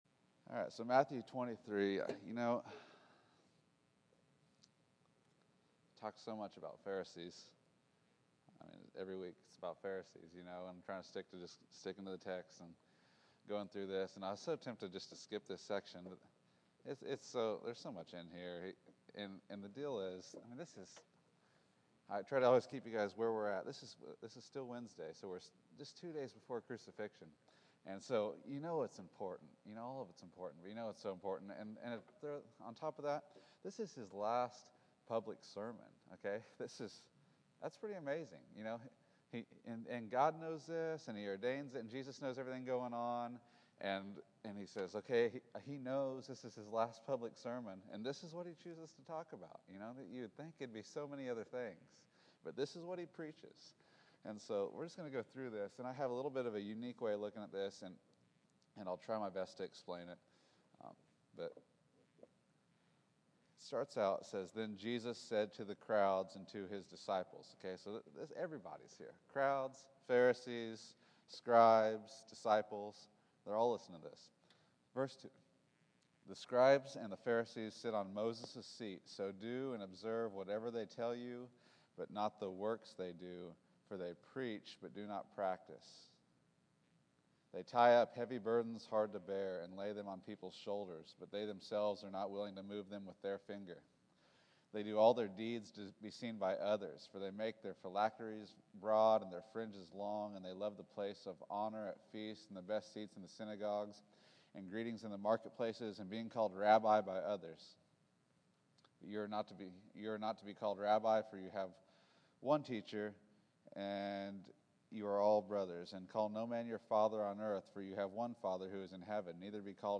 Matthew 23 March 09, 2014 Category: Sunday School | Back to the Resource Library Seven woes to the pharisees, and an exhortation to seek God in Scripture.